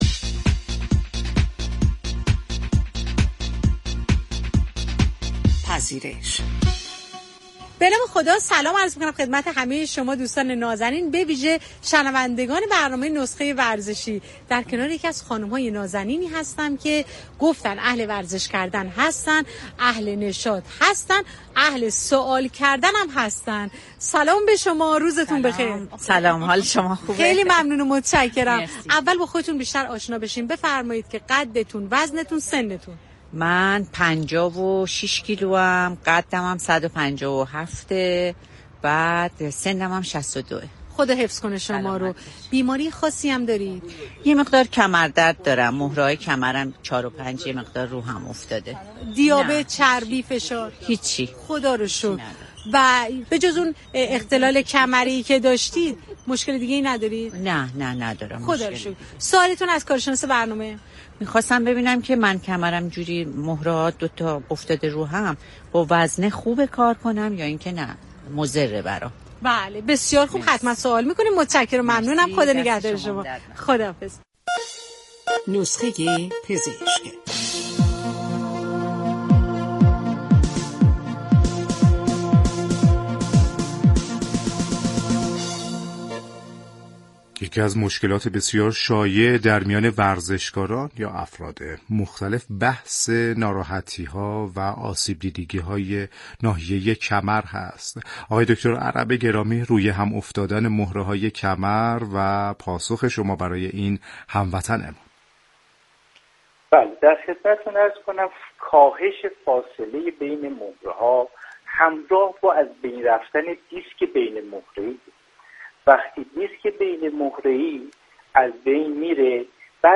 /صوت آموزشی/